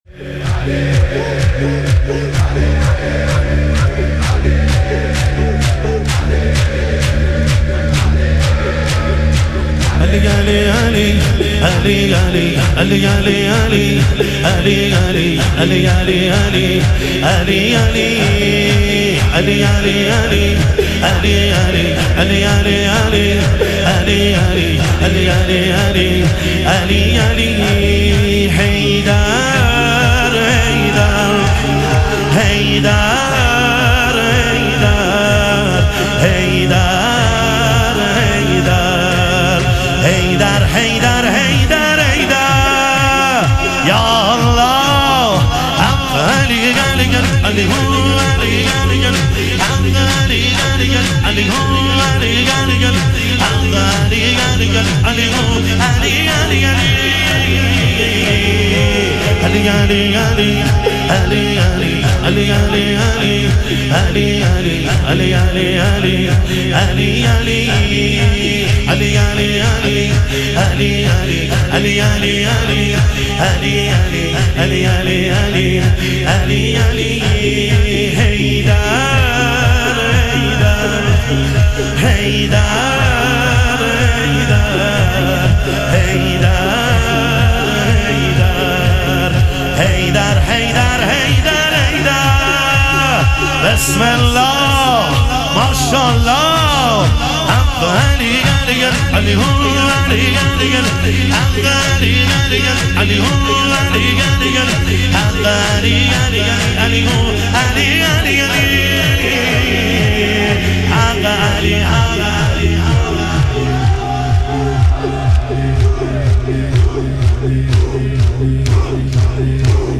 اربعین امیرالمومنین علیه السلام - شور